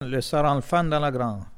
Mots Clé foin, fenaison ; Localisation Saint-Hilaire-de-Riez
Langue Maraîchin
Catégorie Locution